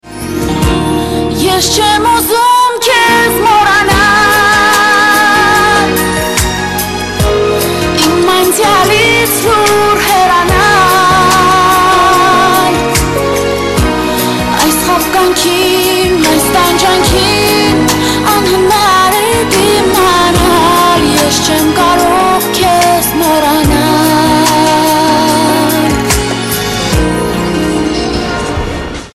поп
женский вокал
армянские
Армянская песня